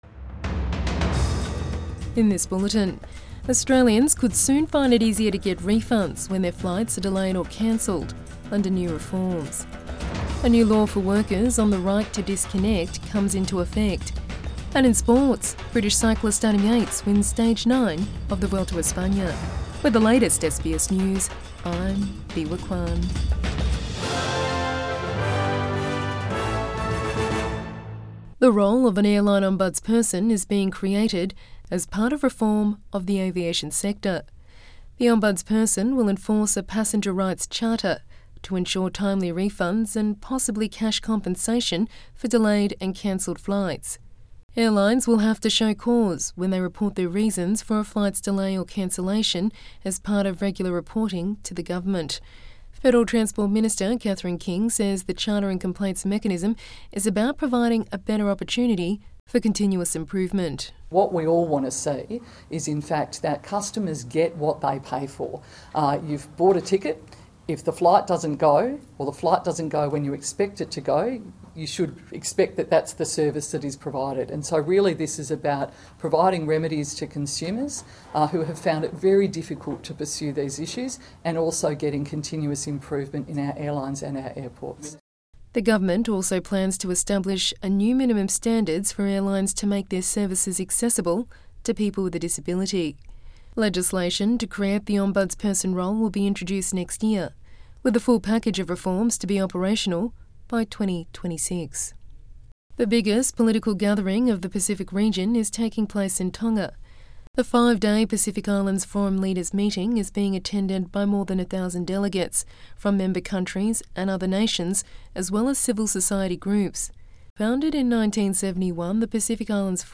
Midday News Bulletin 26 August 2024